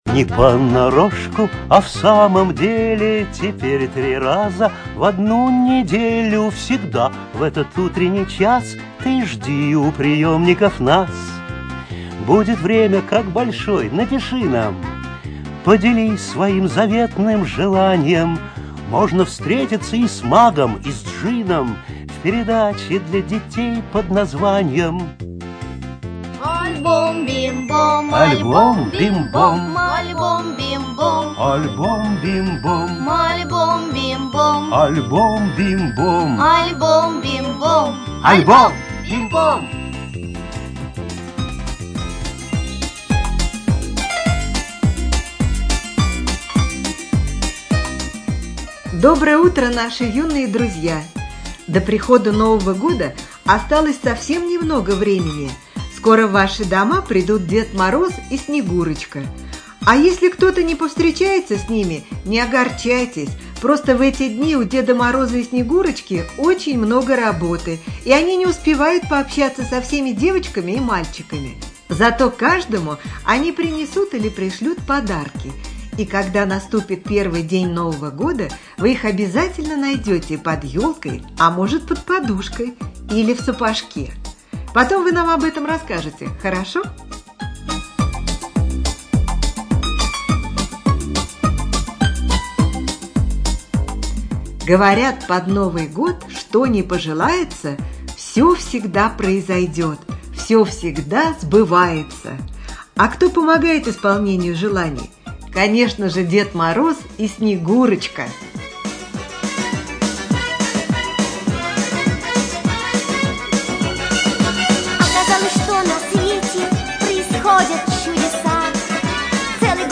ЧитаютТабаков О., Зудина М.